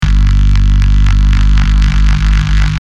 Bass Tones 9 G
Antidote_Zodiac-Bass-Tones-9-G.mp3